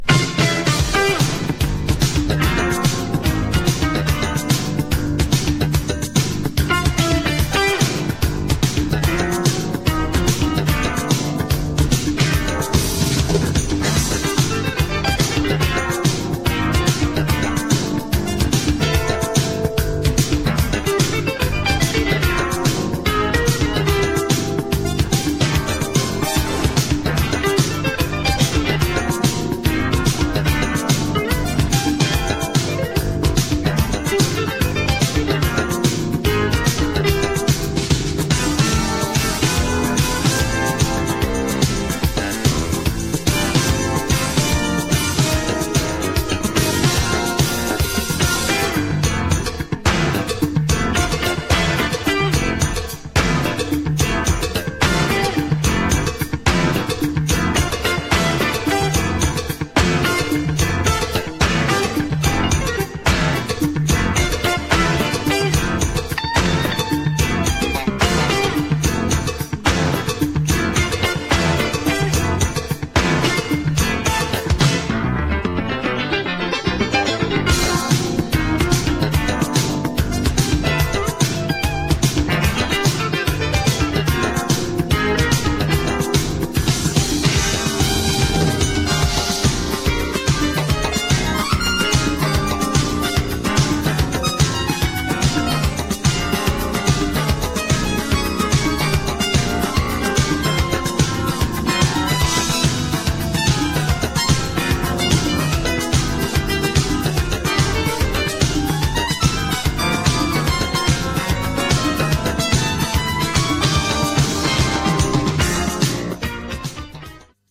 GENRE Dance Classic
BPM 96〜100BPM